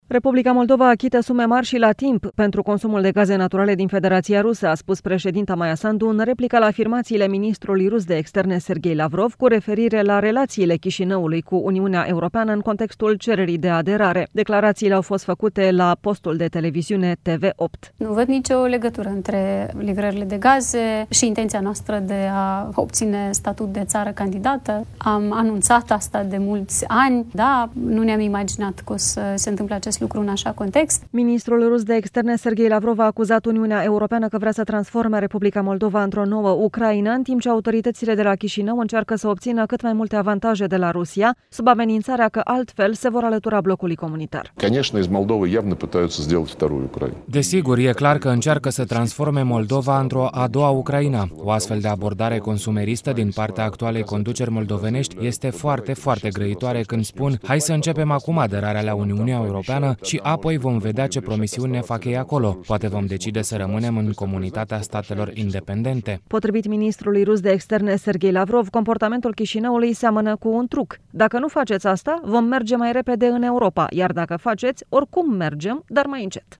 Într-un interviu la TV8, președinta Maia Sandu a subliniat că țara sa este independentă și suverană și cetățenii săi decid dacă va deveni stat membru al blocului comunitar: